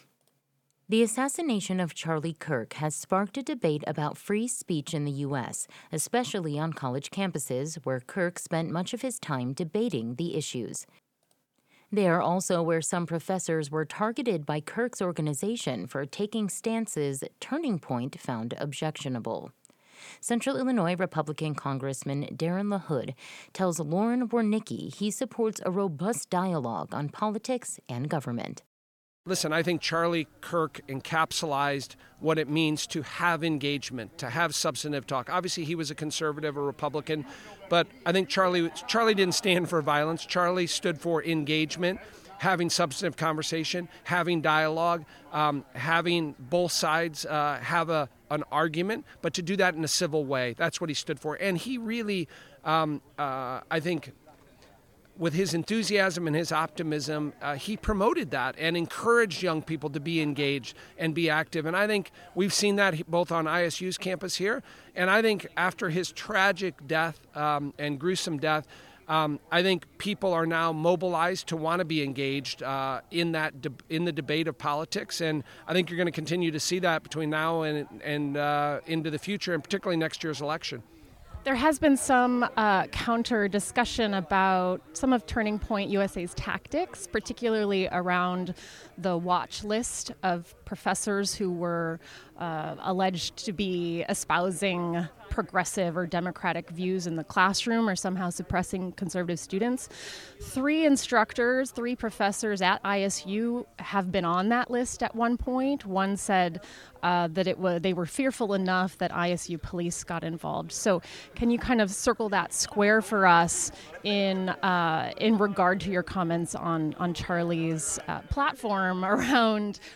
“That’s what Democrats have always supported is a clean CR [continuing resolution],” LaHood said in an interview on WGLT’s Sound Ideas.